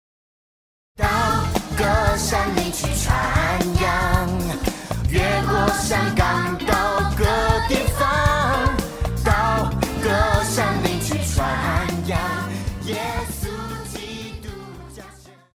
流行-合唱,童聲
樂團
聖誕歌曲,聖歌,流行音樂,教會音樂
歌唱曲
聲樂與伴奏
有主奏
有節拍器